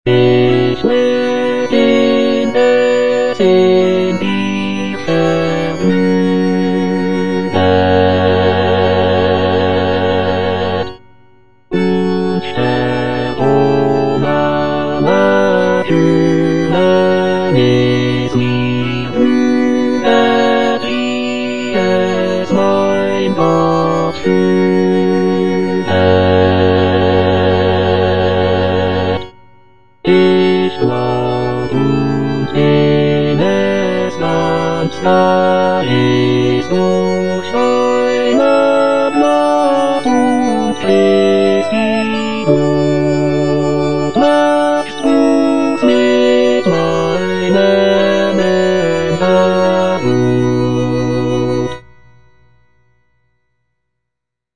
Choralplayer playing Cantata
The work features a joyful and optimistic tone, with the solo soprano expressing gratitude for the blessings in her life. The text explores themes of contentment, trust in God, and the acceptance of one's fate.